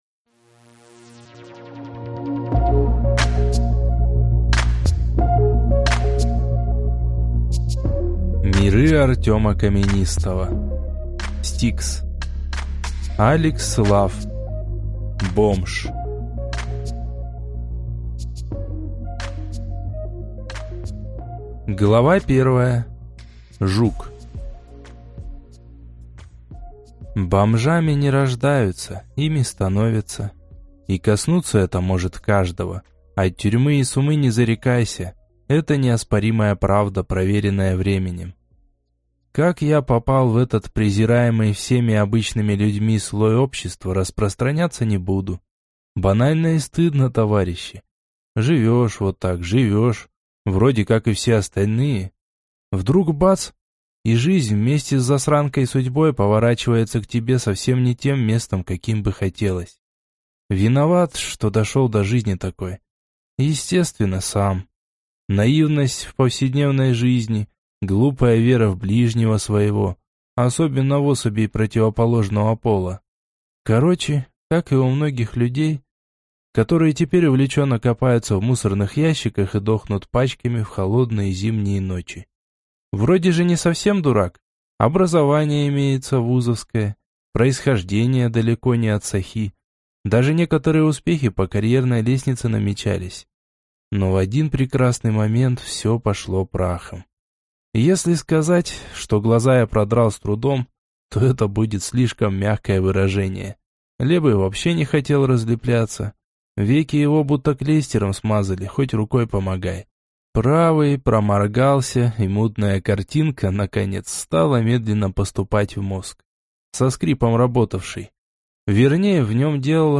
Аудиокнига S-T-I-K-S. БОМЖ | Библиотека аудиокниг